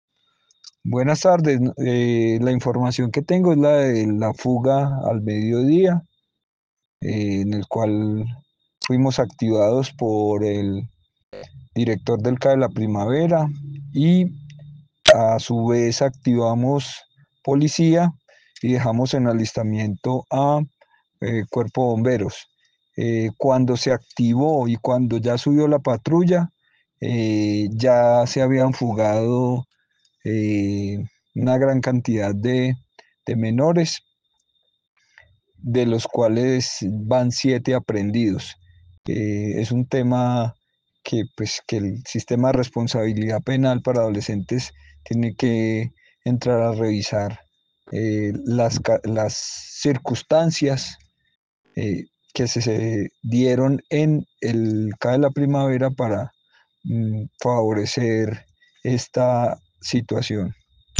Mauricio Cárdenas, secretario gobierno, Montenegro